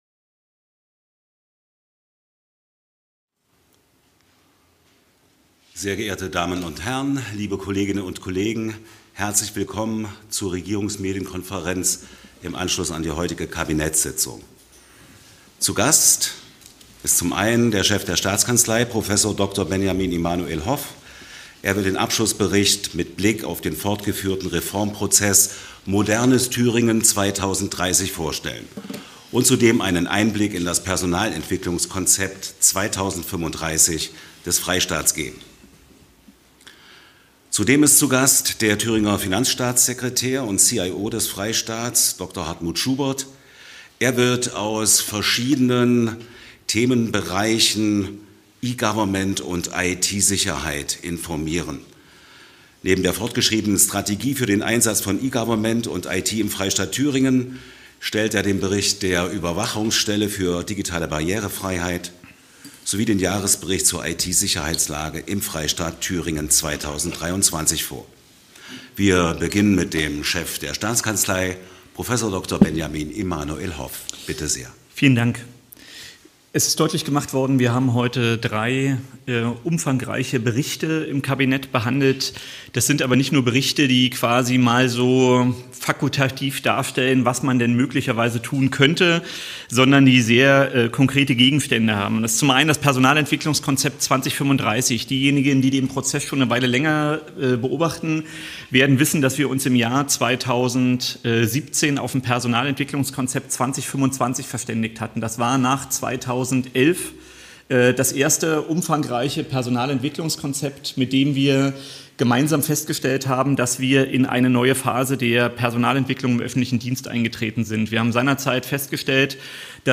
Der Chef der Staatskanzlei Minister Prof. Dr. Benjamin-Immanuel Hoff hat in der Regierungsmedienkonferenz am 11. Juni den Abschlussbericht für den Reformprozess „Modernes Thüringen 2030“ vorgestellt und einen Einblick in das Personalentwicklungskonzept 2035 des Freistaats gegeben.
Der Thüringer Finanzstaatssekretär und CIO Dr. Hartmut Schubert hat in der Regierungsmedienkonferenz über den Stand von E-Government und IT-Sicherheit des Freistaats informiert. Neben der fortgeschriebenen Strategie für den Einsatz von E-Government und IT des Freistaats Thüringen stellte Schubert den Bericht der Überwachungsstelle für digitale Barrierefreiheit sowie den Jahresbericht zur IT-Sicherheitslage im Freistaat Thüringen 2023 vor.